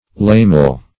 lamel - definition of lamel - synonyms, pronunciation, spelling from Free Dictionary Search Result for " lamel" : The Collaborative International Dictionary of English v.0.48: Lamel \Lam"el\, n. See Lamella .